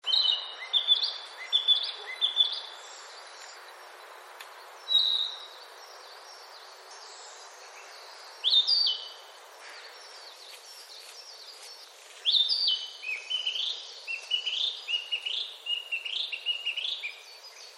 キビタキ （スズメ目ヒタキ科）
オスは5月の終わり頃からさえずります。声の美しい鳥として有名ではありませんが、草原のピッコロ奏者と表現する人もいるくらいに実際にはとても大きな美しい声です。